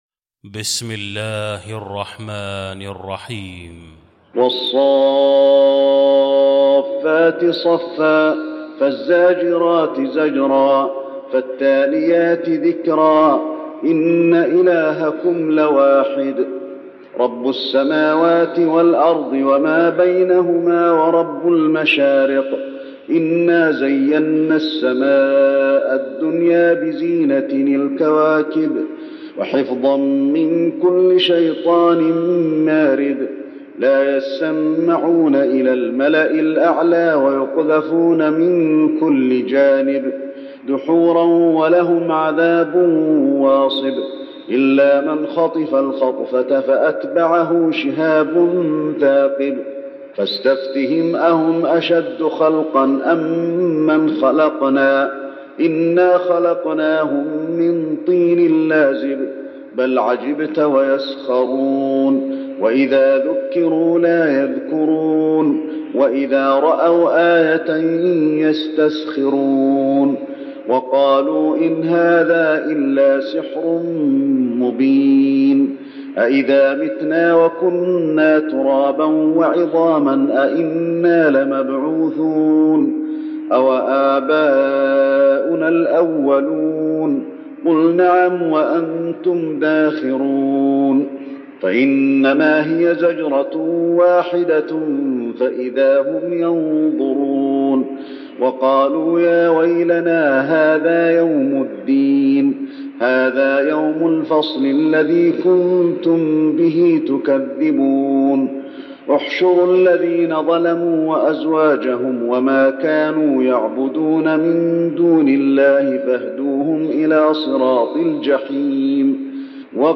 المكان: المسجد النبوي الصافات